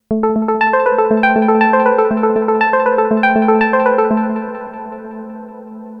Novation Peak – Klangbeispiele
novation_peak_test__arpeggio_3.mp3